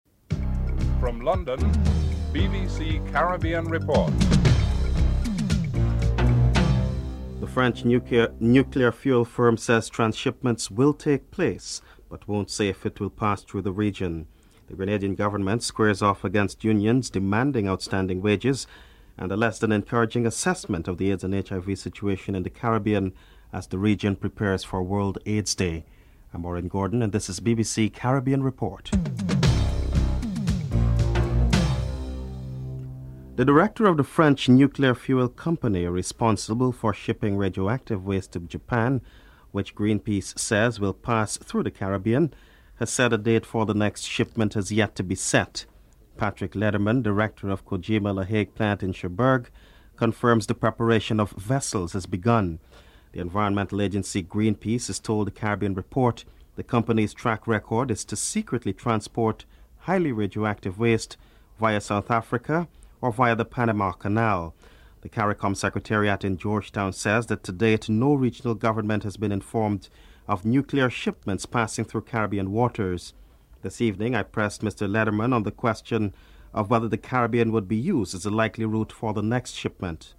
1. Headlines (00:00-00:33)
Member of Parliament Diane Abbott is interviewed (12:36-15:26)